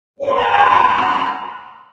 sounds / monsters / poltergeist / attack_3.ogg